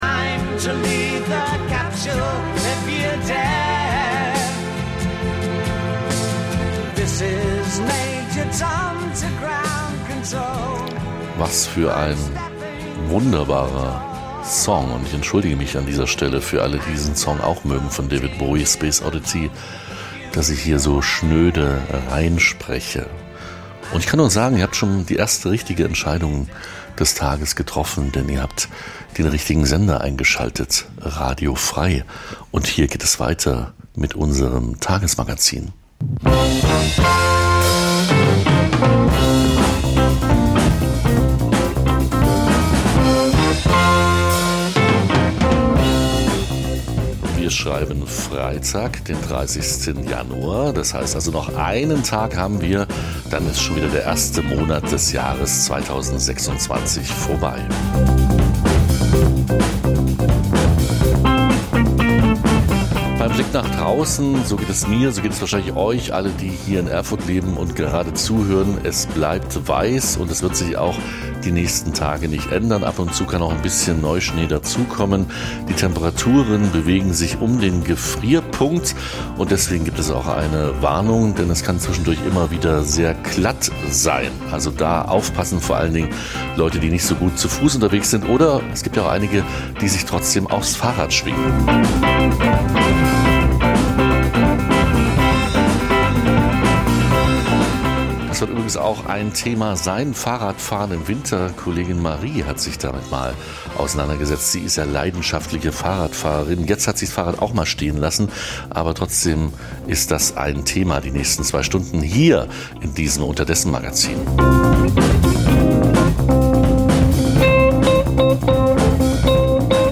Das tagesaktuelle Livemagazin sendet ab 2024 montags bis freitags 9-11 Uhr.